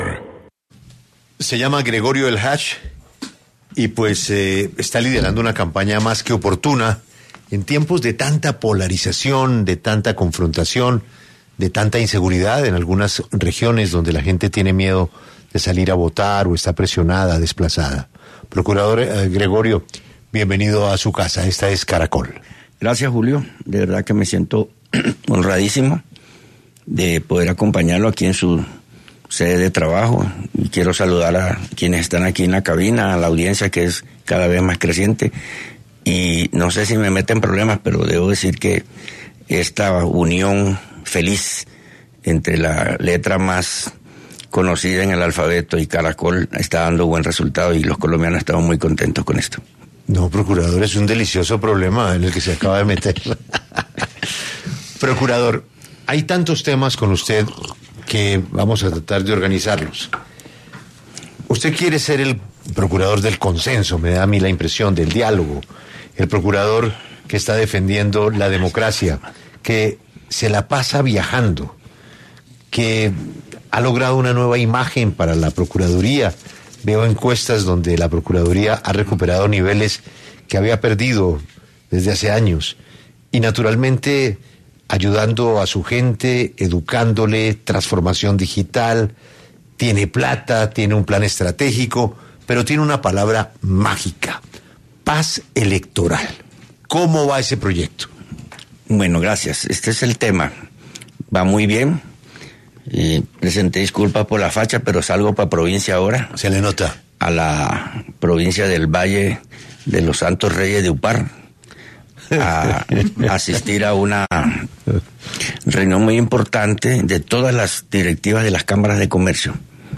El procurador general, Gregorio Eljach, pasó por los micrófonos de 6AM W para hablar sobre la paz electoral y el trabajo que se viene adelantando para garantizar una jornada de elecciones segura y trasparente en Colombia.